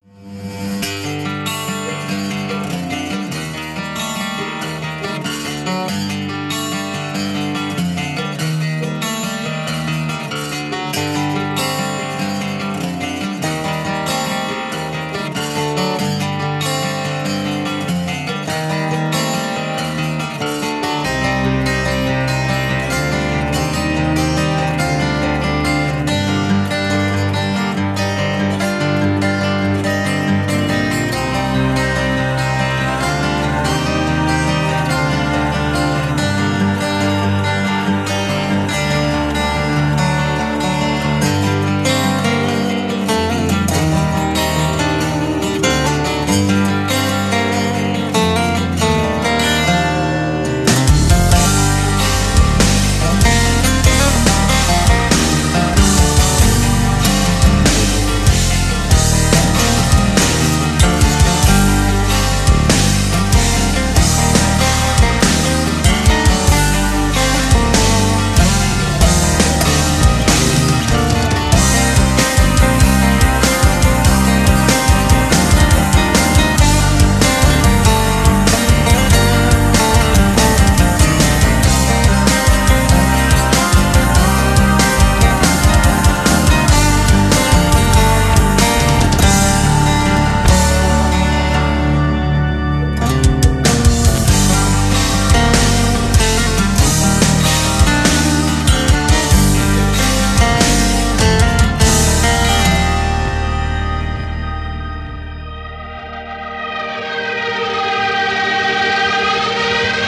Жанр: Power Metal